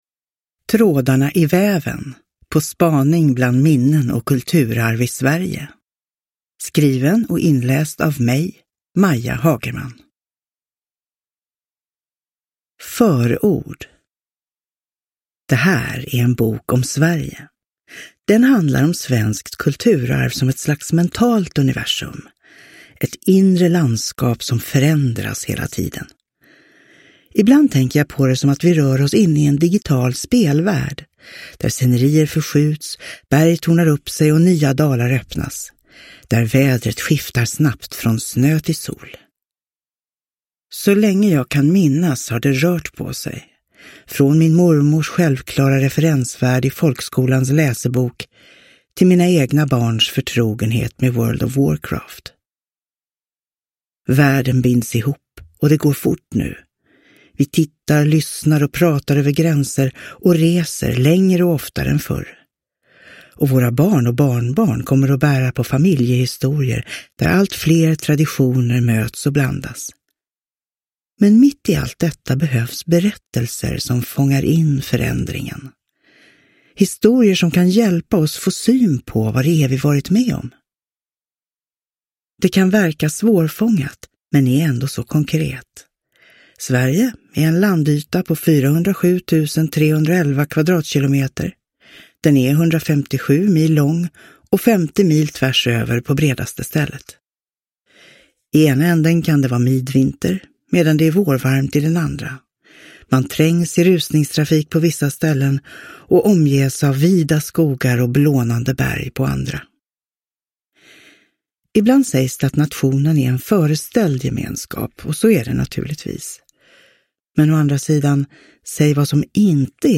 Trådarna i väven : på spaning bland minnen och kulturarv i Sverige – Ljudbok – Laddas ner